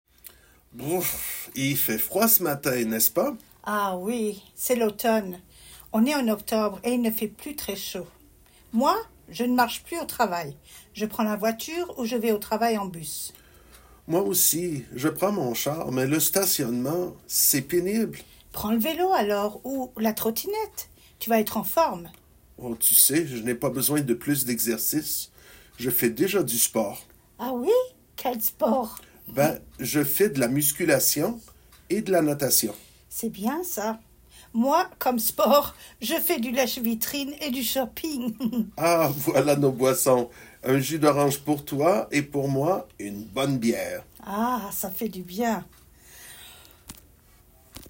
Dialogue – Un 6 | FrenchGrammarStudio